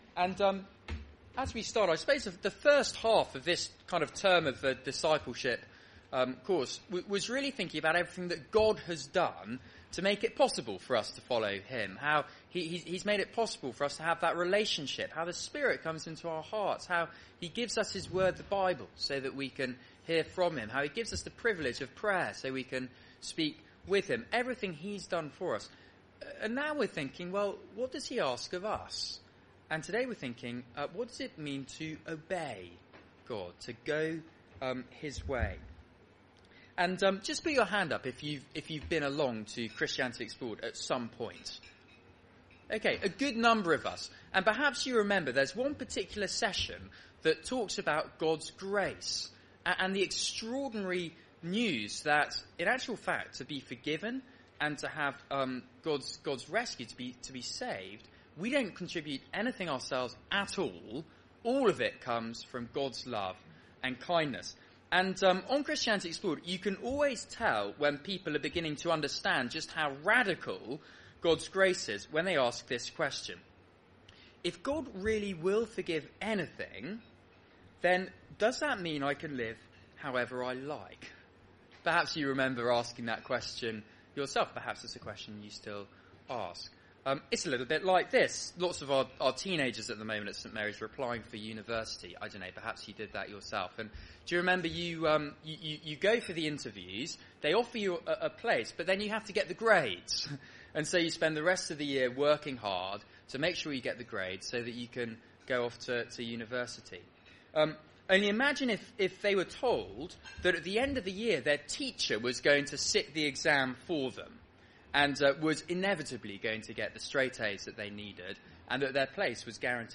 Media for Seminar on Thu 06th Nov 2014 19:30 Speaker